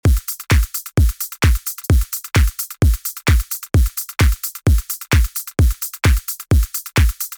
Runner_130 – Drums_Full
bass house construction kit drops
Runner_-4-Runner_130-Drums_Full.mp3